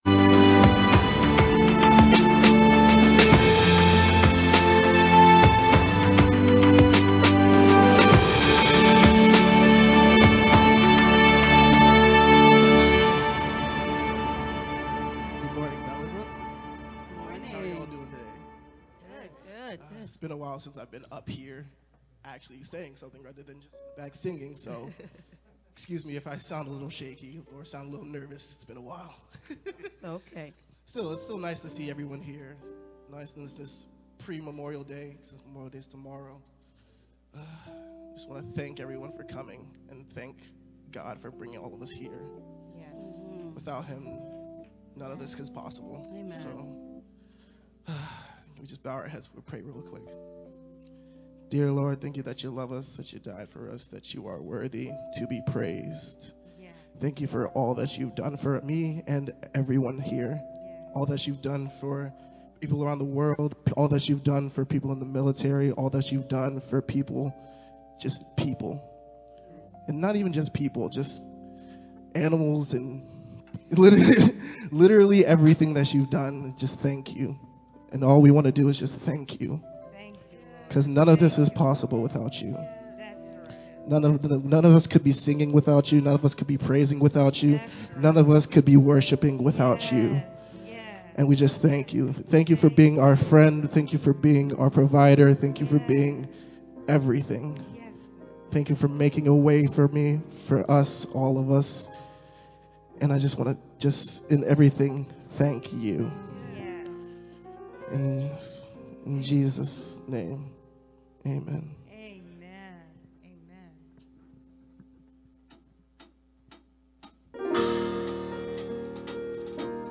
May-29th-VBCC-Sermon-edited-Mp3-CD.mp3